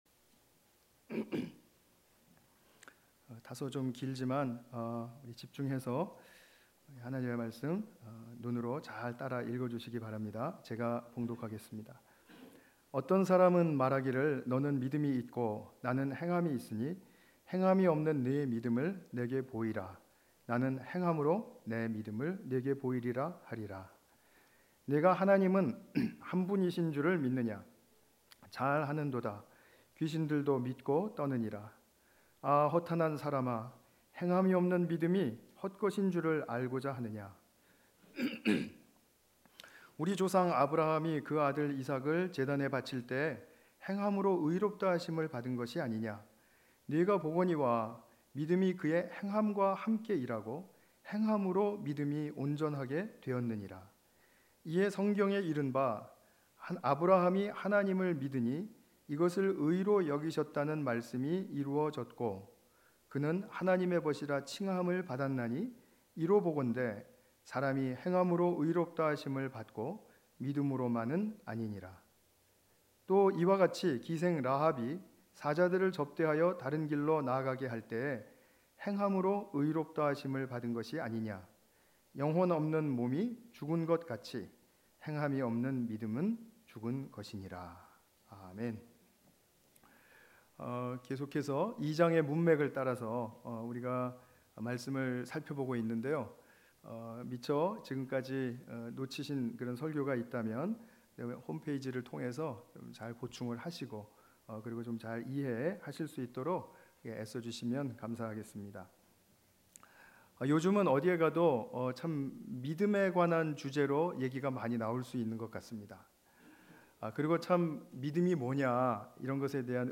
야고보서 2:18-26 관련 Tagged with 주일예배 Audio